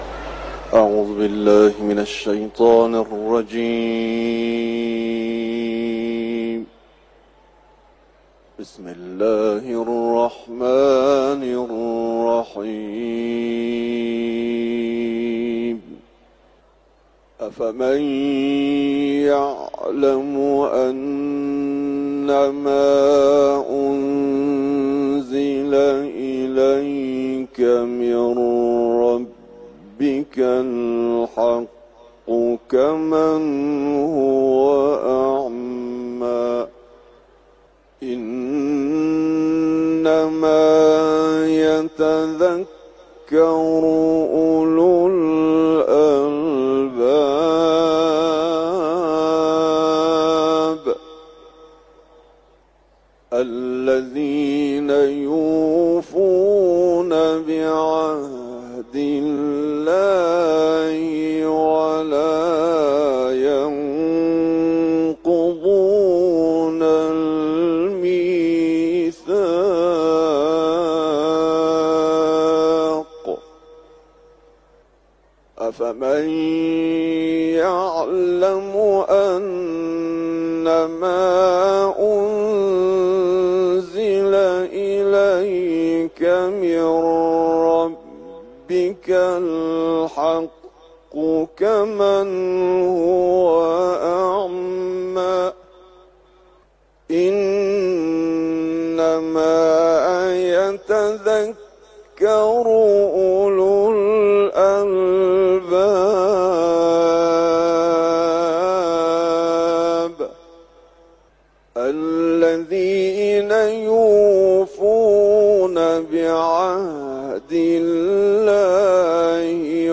صوت تلاوت آیات ۱۹ تا ۲۴ از سوره «رعد»